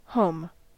Ääntäminen
USA: IPA : /ˈhoʊm/